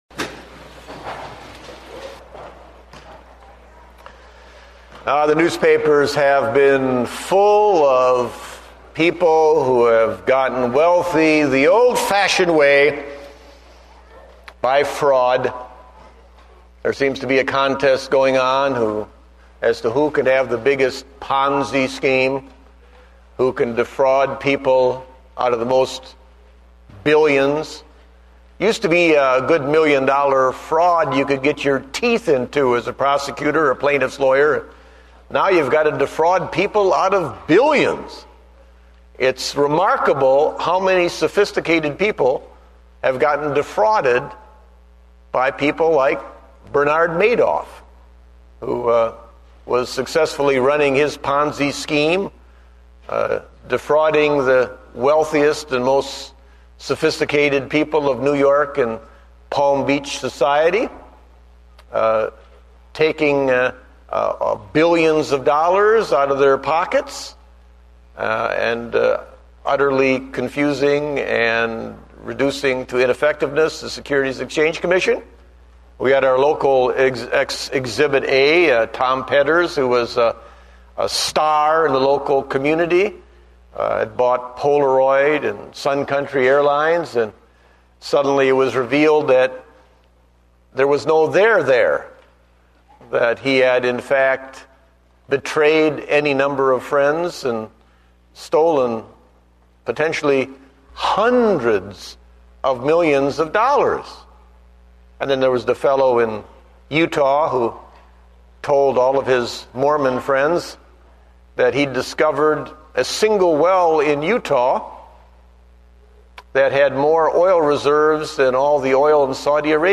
Date: March 29, 2009 (Morning Service)